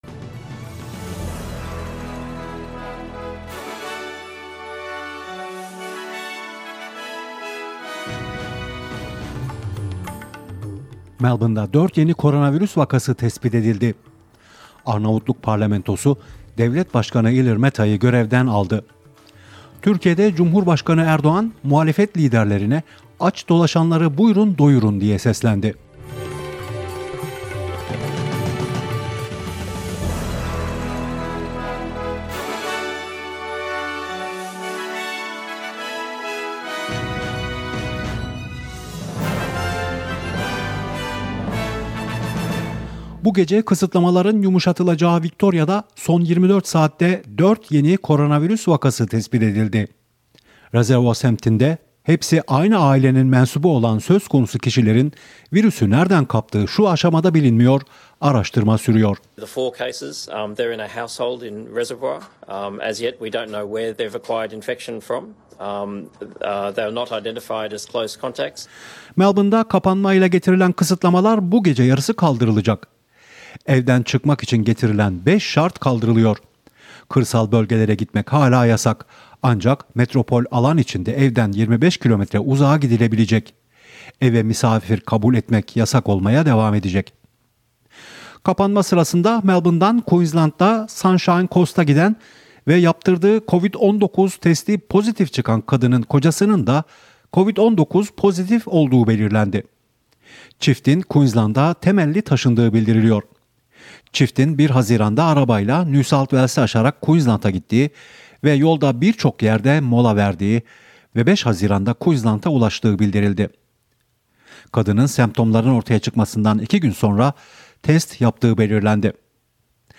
SBS Türkçe Haber Bülteni Source: SBS